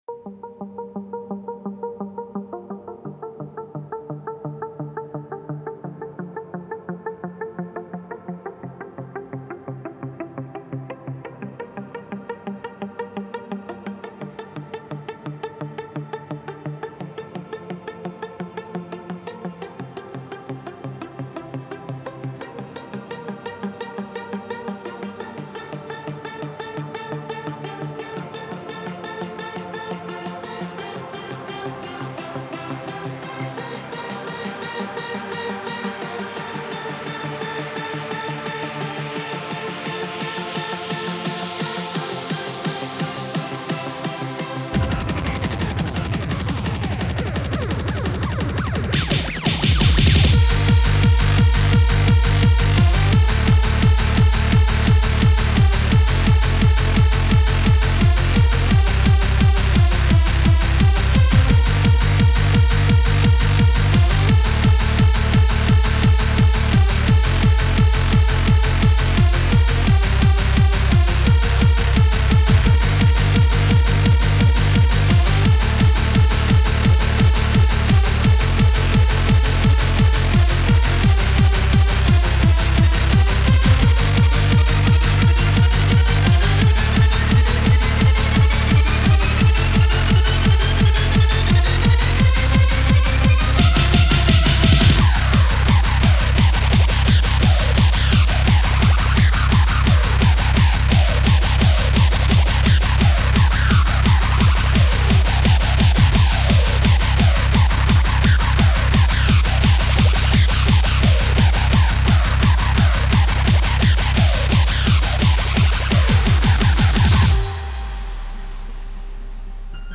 Ascend (Hard Trance / Freeform Hardcore
Jo hier mal mein aktuelles werk, ist bis jetzt nur grob gemischt und gelevelt, es geht mir jetzt erstmal nur um die komposition, aufbau, spannungsbogen...
geh hier schon auf die 100 stunden arbeitszeit zu. fast alles nochma überarbeitet, paar neue spuren, neue abmischung neues mastering....